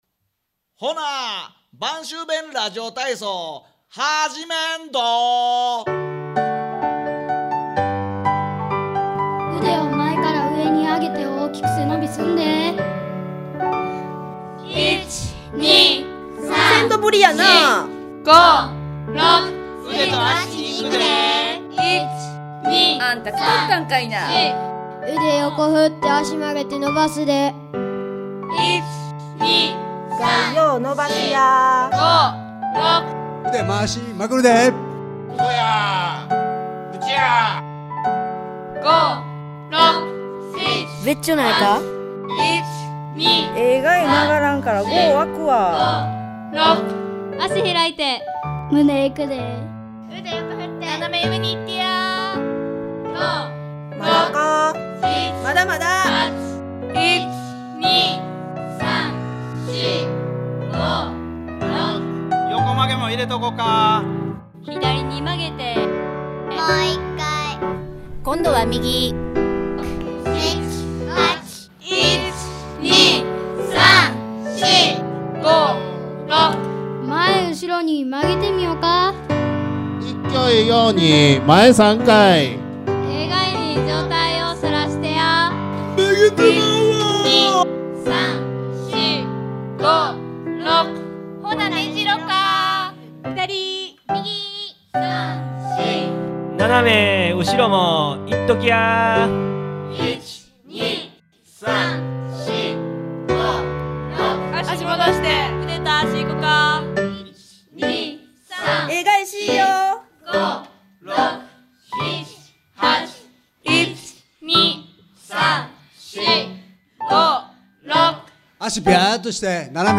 家庭内で楽しくできる播州弁ラジオ体操
威勢の良いかけ声に元気がもらえます。
播州弁ラジオ体操 (音声ファイル: 3.1MB)